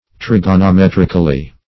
[1913 Webster] --Trig`o*no*met"ric*al*ly, adv.